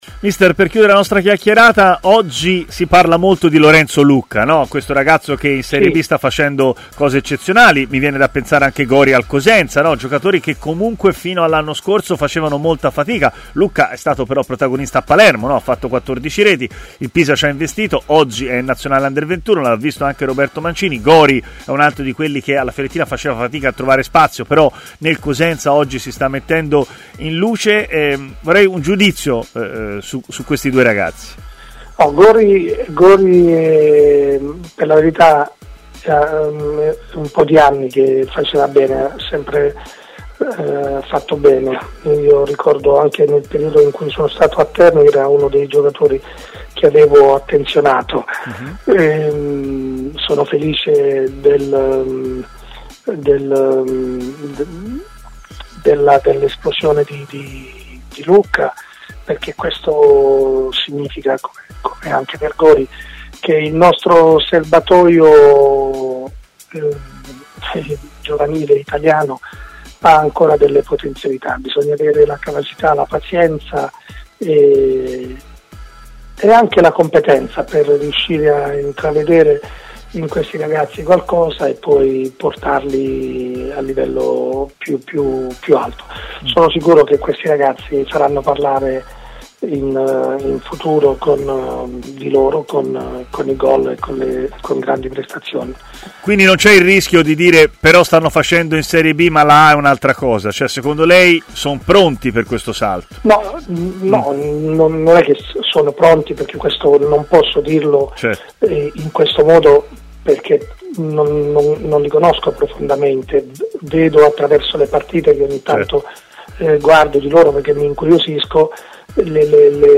L'allenatore Luigi De Canio è intervenuto in diretta a Stadio Aperto, trasmissione di TMW Radio, dove ha parlato anche di due giocatori come Lucca e Gori, uno nel mirino della Fiorentina, l'altro di proprietà dei viola.